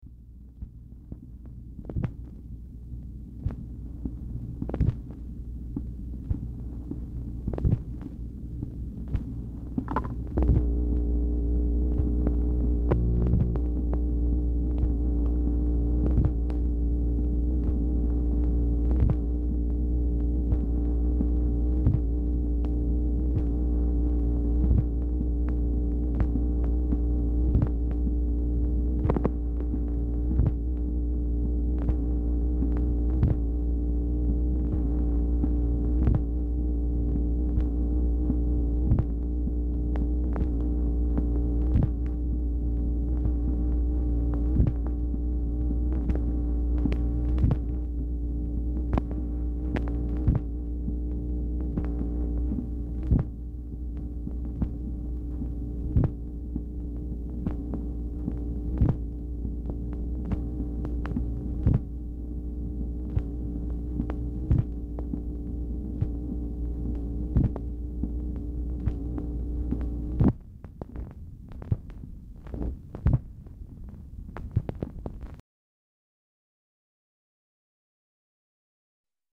Telephone conversation # 1944, sound recording, MACHINE NOISE, 2/7/1964, time unknown | Discover LBJ
Telephone conversation
Format Dictation belt